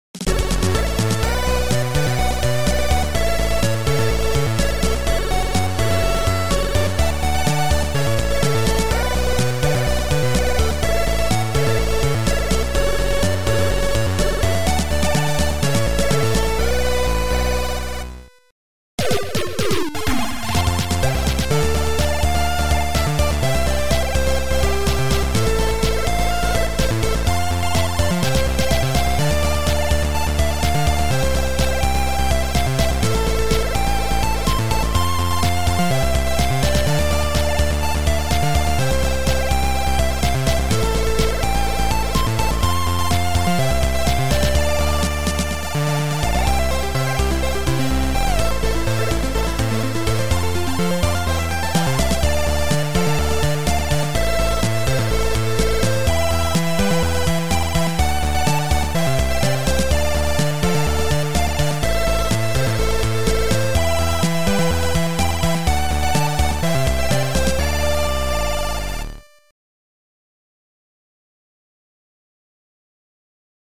Sound Format: Noisetracker/Protracker
Sound Style: Chip